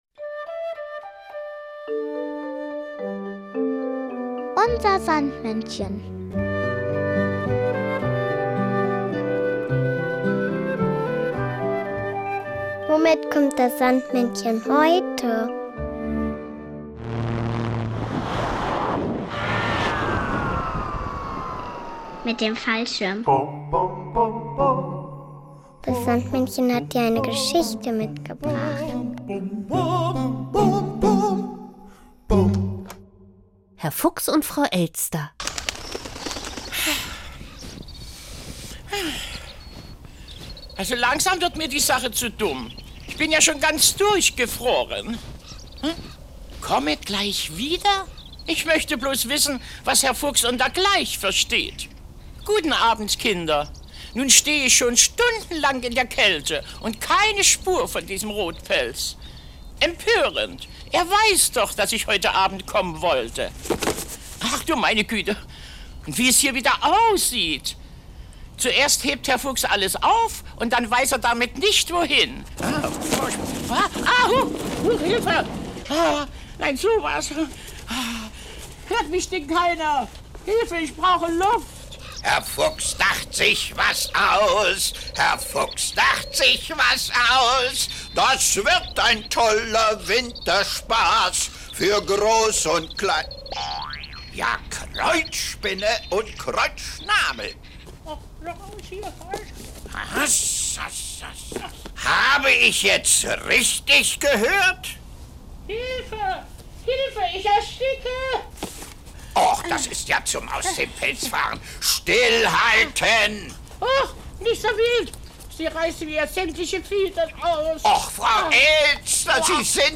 Kinderlied von Christoph Goetten aus dem Film "Lotte im Dorf der